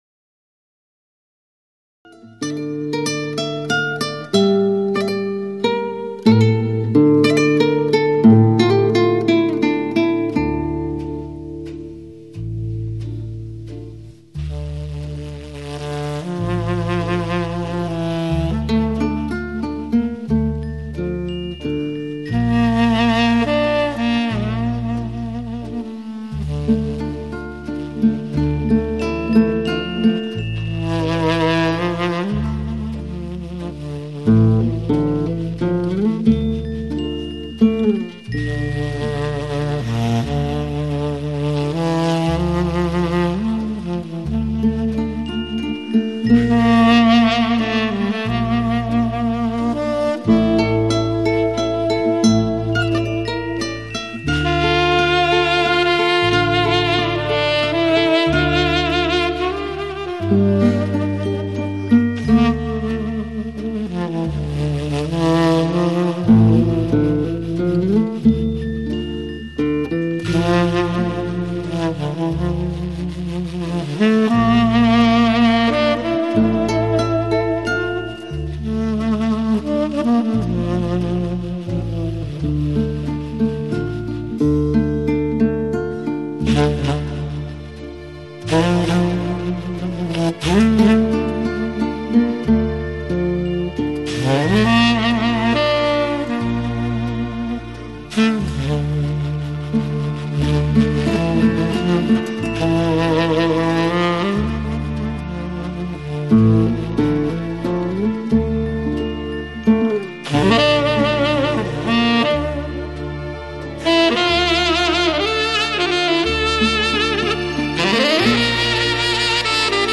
薩克斯風